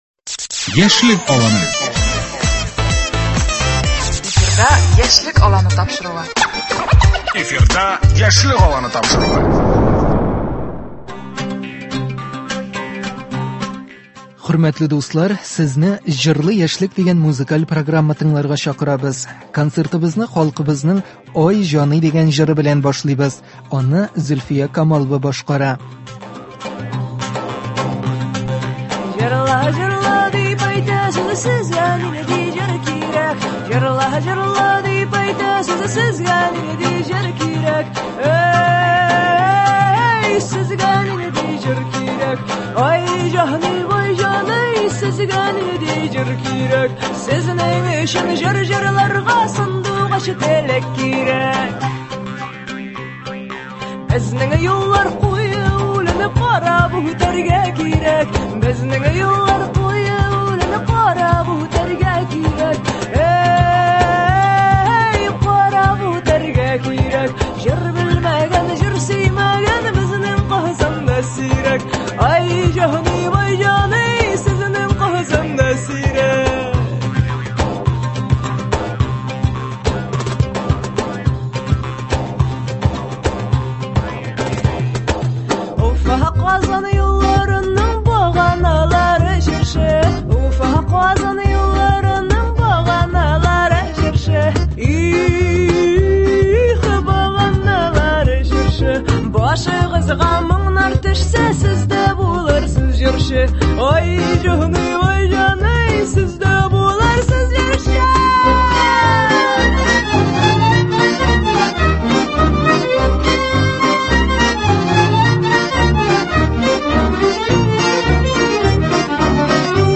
Яшь башкаручылар чыгышы.